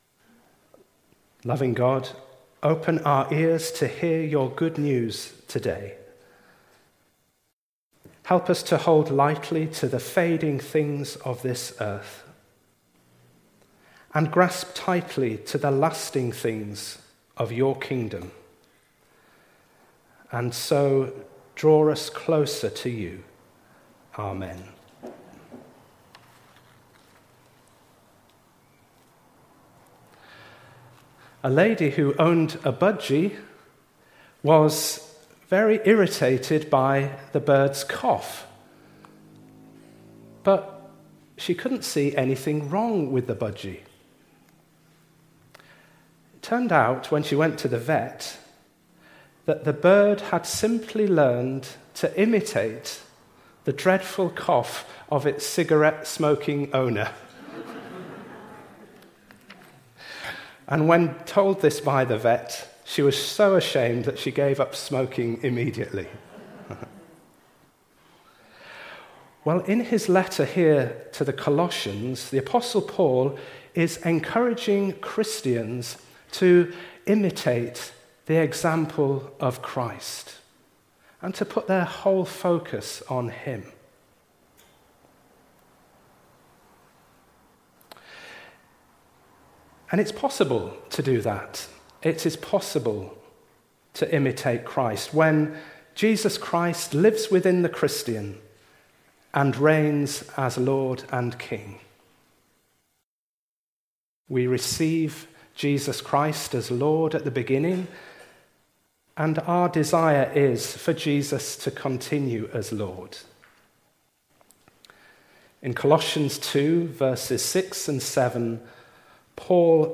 An audio version of the sermon is also available.